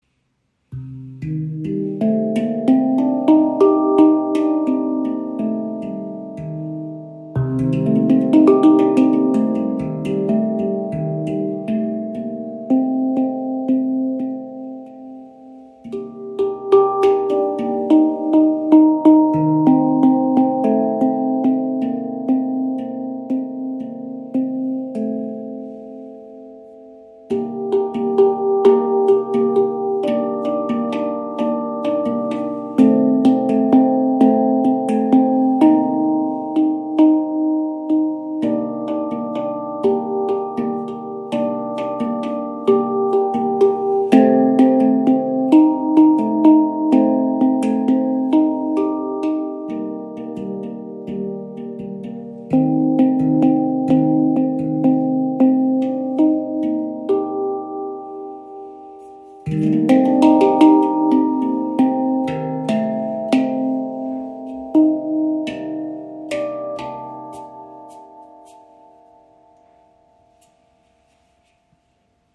Entdecke die Handpan-Stimmung La Sirena in C: weich, träumerisch und emotional.
Klangbeispiel
Die ShaktiPan vereint weichen Anschlag, langes Sustain und berührende Klangtiefe – perfekt für Meditation, Yoga und Klangarbeit.
Handpan Shaktipan | C La Sirena | 9 Klangfelder La Sirena in C berührt mit weichen, träumerischen Klängen und lädt zu tiefer Meditation und freiem Spiel ein.